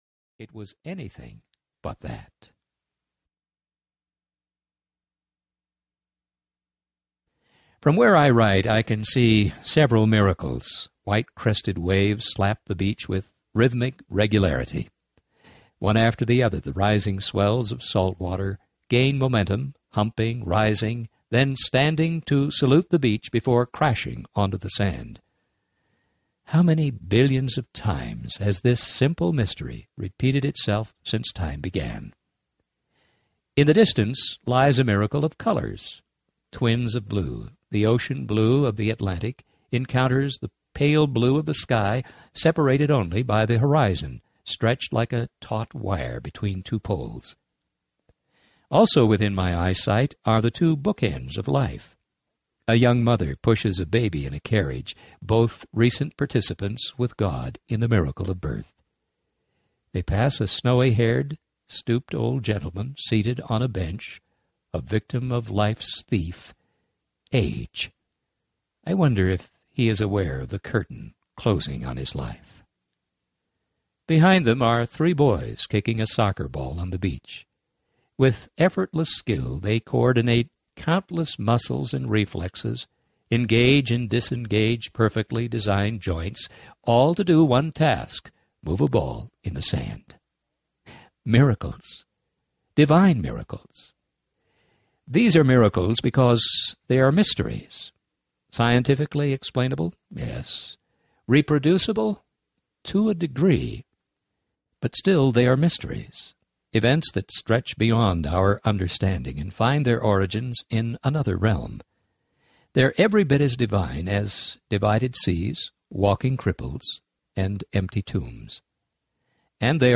God Came Near Audiobook
Narrator
2.9 Hrs. – Unabridged